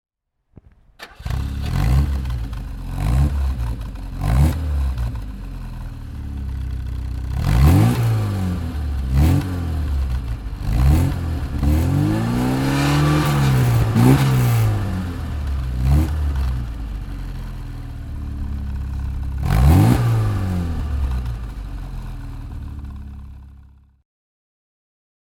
Siata 1300 Coupé 2+2 (1962) - Starten und Leerlauf